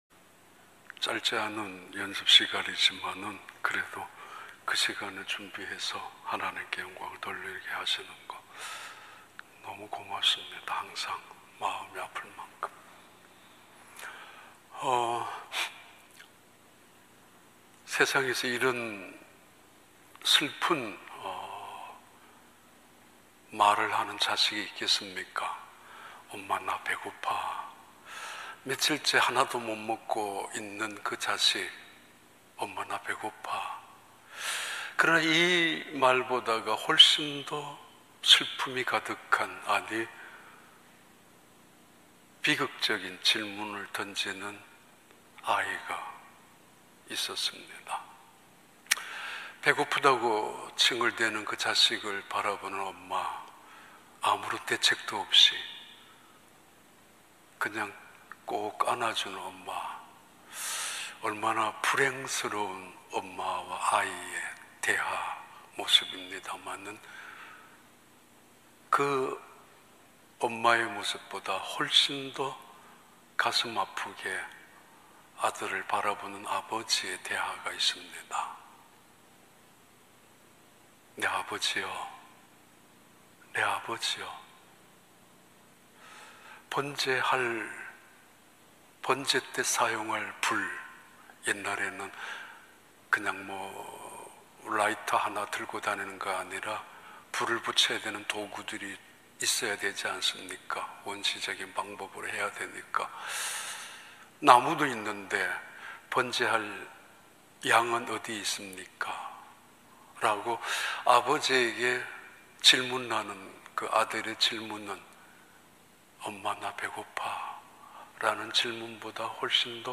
2022년 2월 20일 주일 3부 예배